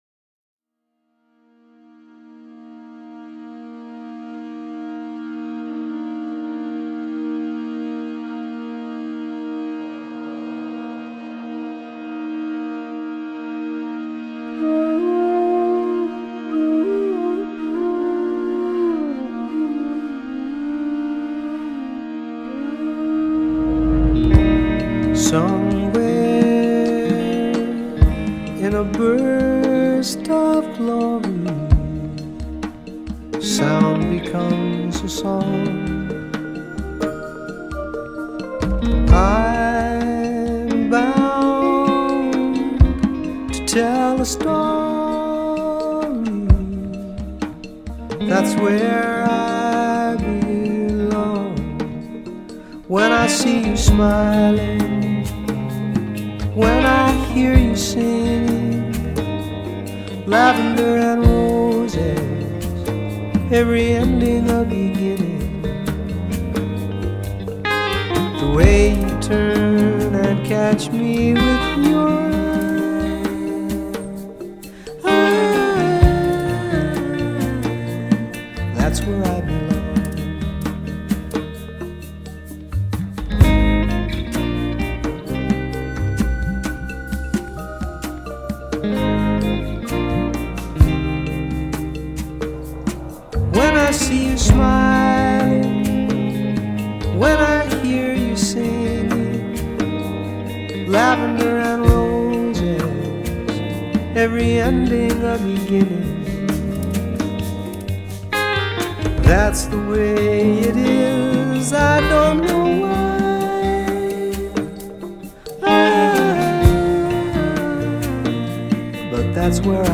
studio album